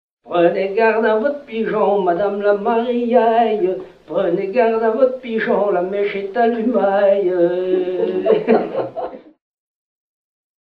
gestuel : à marcher ;
circonstance : fiançaille, noce ;
Genre laisse
Catégorie Pièce musicale éditée